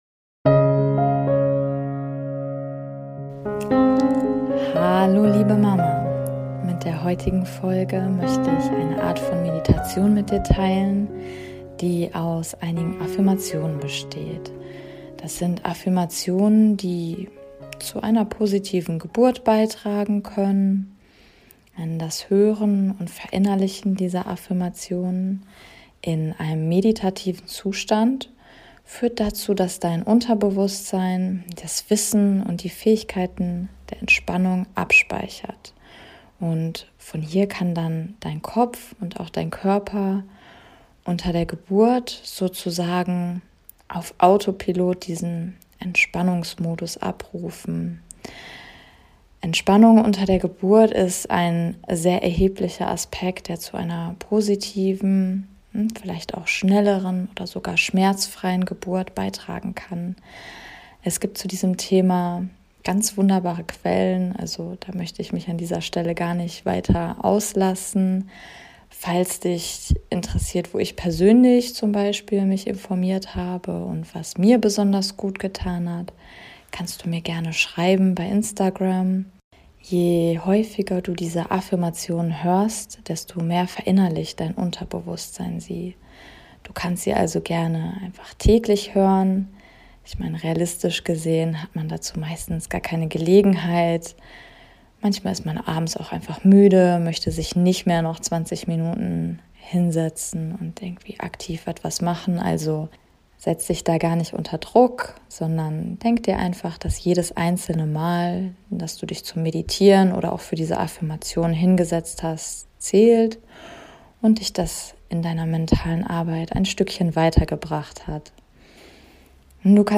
#002 - Affirmationen für eine positive Geburt ~ Meditationen für die Schwangerschaft und Geburt - mama.namaste Podcast